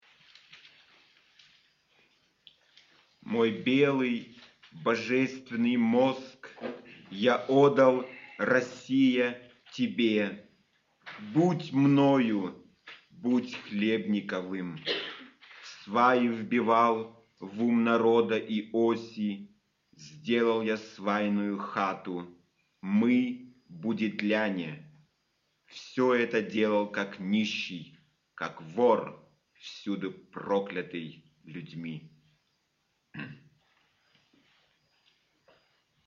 Mittschnitt aus der Veranstaltung „Segel der Zeit“ anlässlich des hundertsten Geburtstags von Welimir Chlebnikov im Lindenau Museum Altenburg 1985.